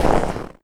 STEPS Snow, Run 05.wav